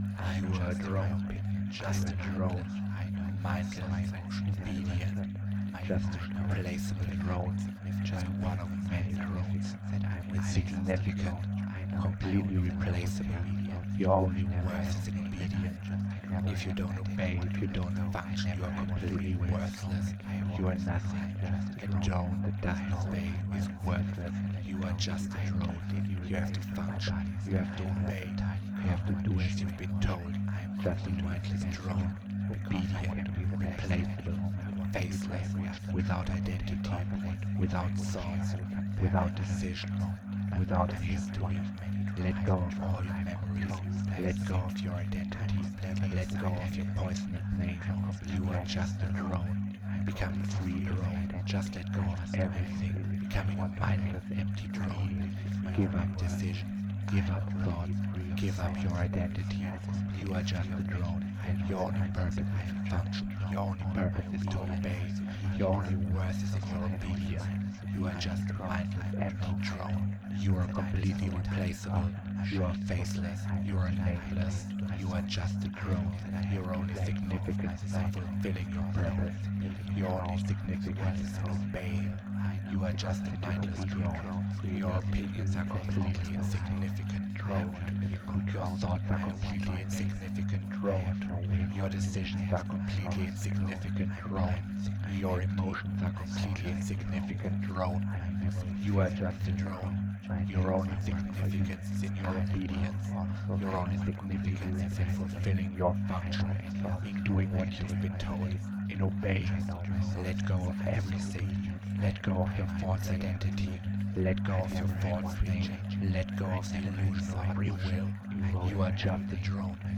This is a little drone loop with additional subliminals and a binaural that works for looping. Something for added drone brainwashing and reinforcement. just turn it on in the background and slowly feel your identity fading away…
Identity-Eraser-Drone-Loop.mp3